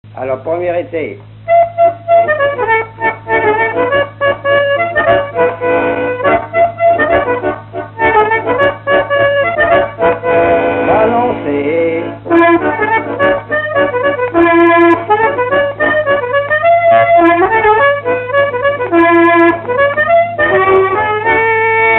Mémoires et Patrimoines vivants - RaddO est une base de données d'archives iconographiques et sonores.
quadrille
instrumental
Pièce musicale inédite